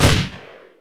/gesource/etc maps, sounds./audio/weapons/ar33/
ar33_fire1_npc.wav